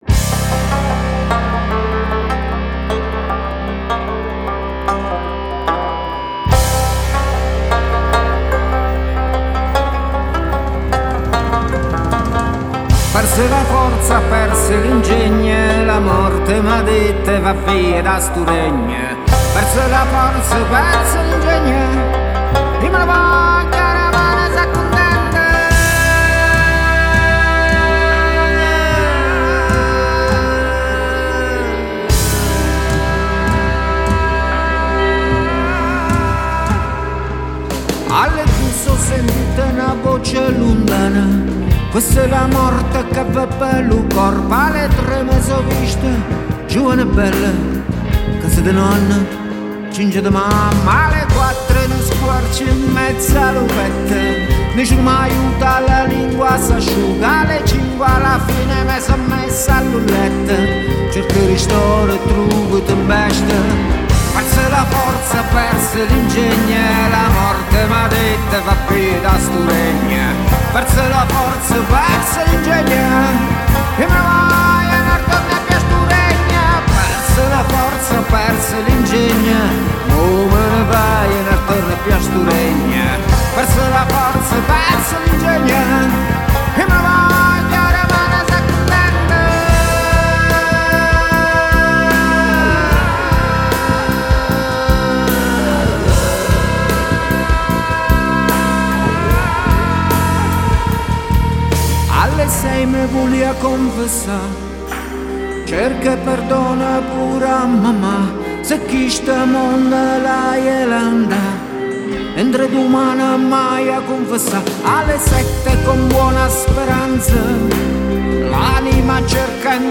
Si sente il folk, e la ritmica è moderna.